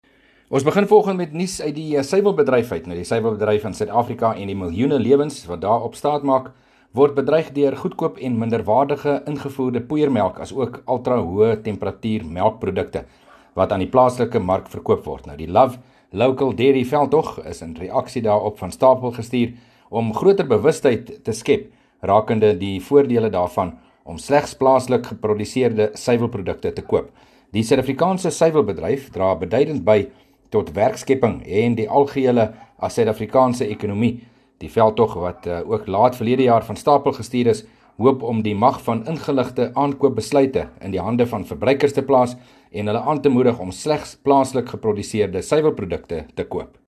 13 Jan PM berig oor ‘n nuwe suiwelprojek